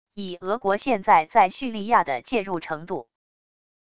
以俄国现在在叙利亚的介入程度wav音频生成系统WAV Audio Player